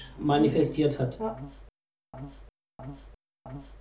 Das Diktiergerät war immer bei uns, ebenso der GaussMaster.
Vorabbegehung:
EVP: